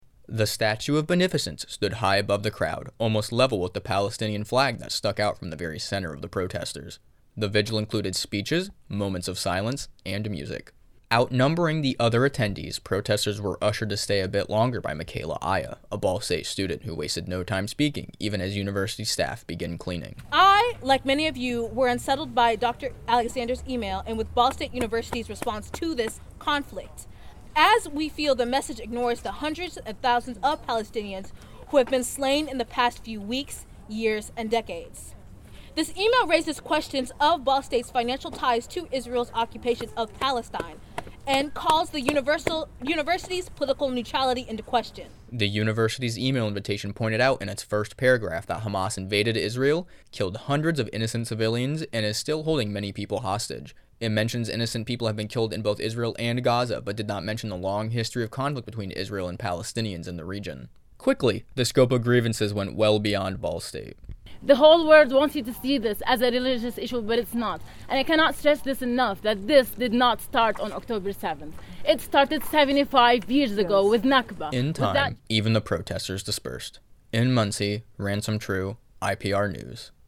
The vigil included speeches, moments of silence, and music.